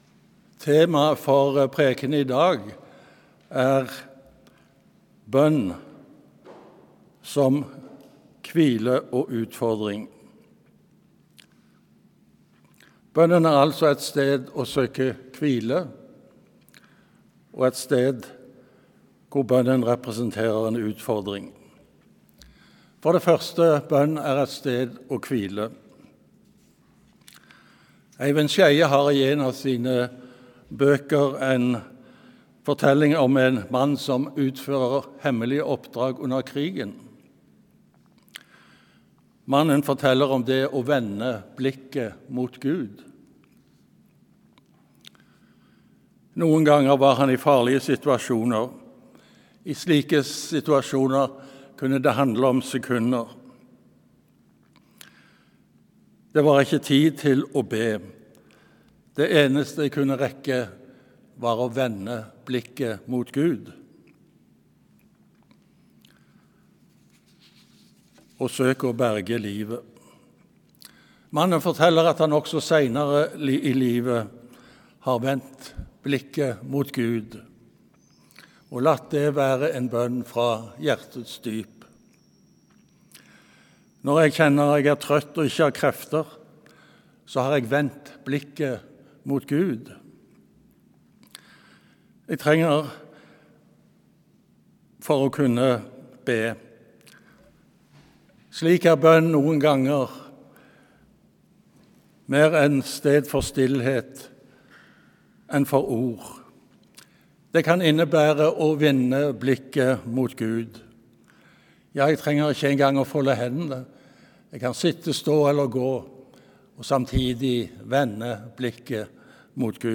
Prekentekst: Matt 7,7–12 Lesetekst 1: Dan 9,17–19 Lesetekst 2: 3 Joh 1,11